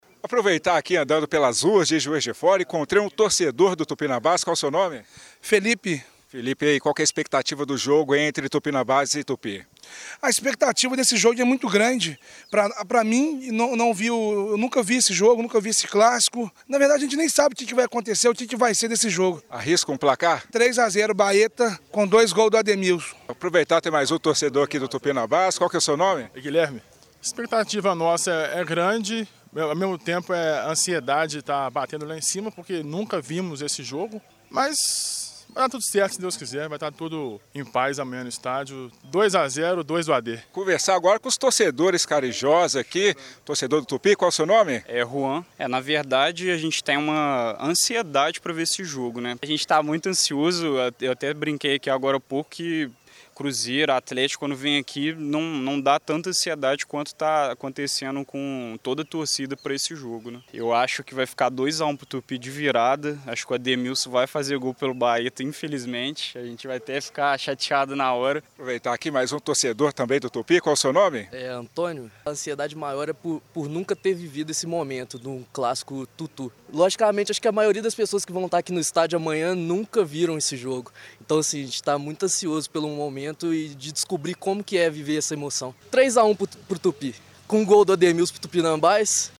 O clássico movimenta a cidade, com isso nossa reportagem foi às ruas da cidade ouvir o sentimento dos torcedores.